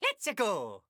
Mario when a course is selected in Super Mario Bros. Wonder.
Mario_-_LetsaGo_-_SMBW.oga.mp3